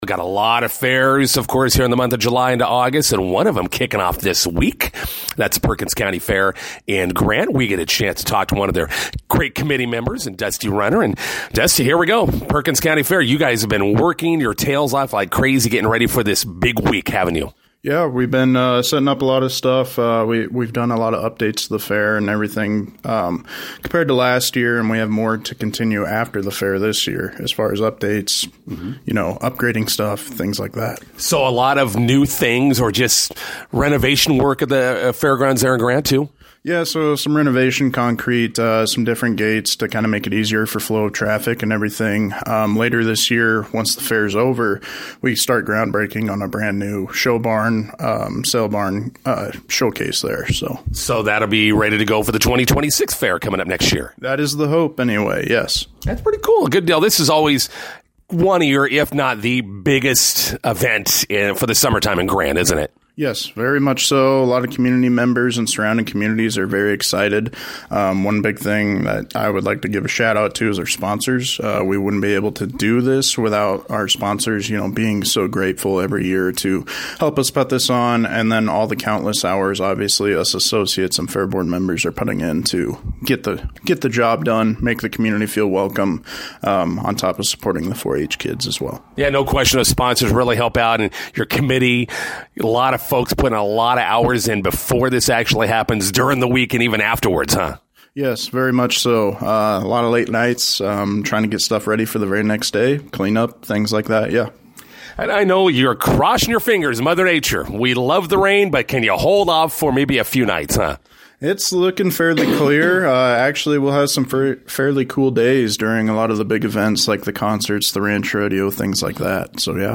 INTERVIEW: Perkins County Fair events kick off today in Grant.